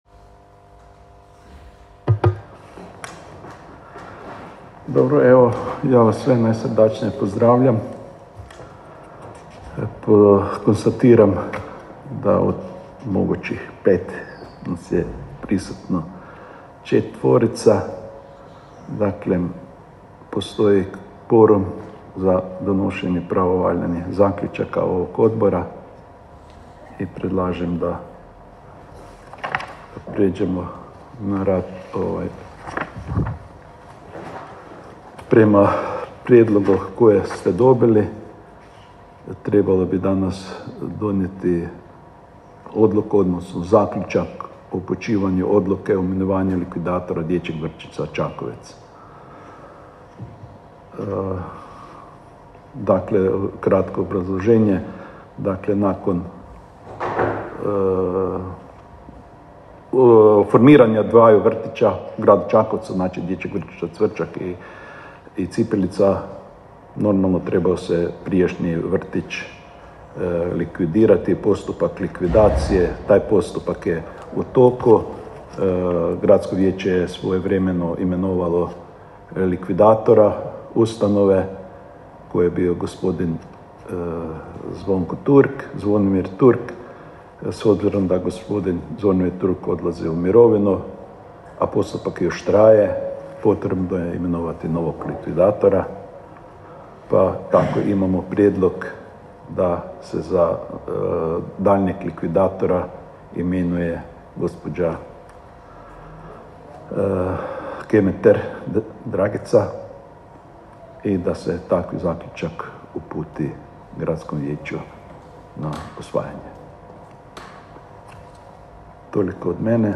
Obavještavam Vas da će se 6. sjednica Odbora za izbor i imenovanje Gradskog vijeća Grada Čakovca održati 25. travnja 2022. (ponedjeljak) u 10.00 sati, u vijećnici, u Upravi Grada Čakovca, K. Tomislava 15, Čakovec.